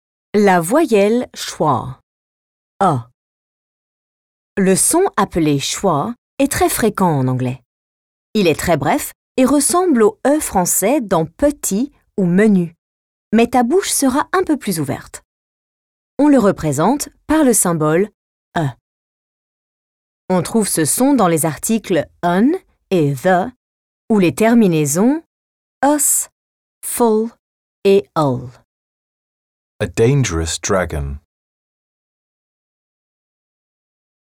U6 • Pronunciation • La voyelle schwa /ə/